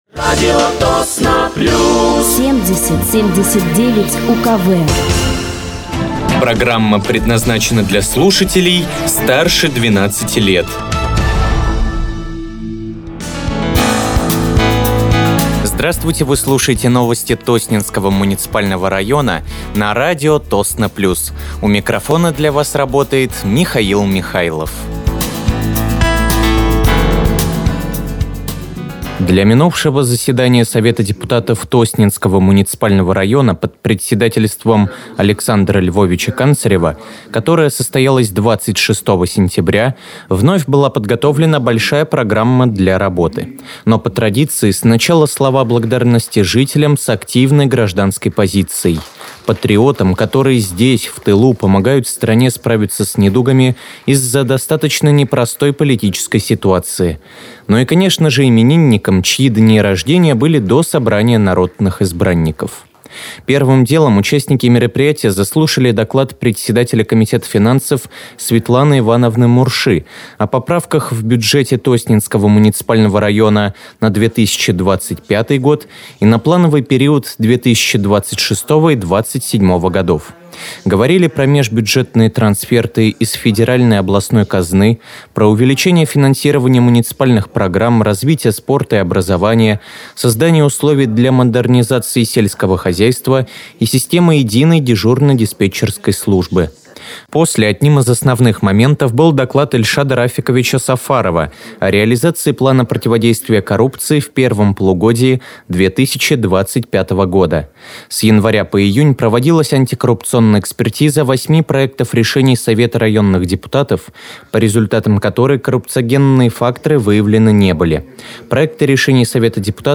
Вы слушаете новости Тосненского муниципального района на радиоканале «Радио Тосно плюс».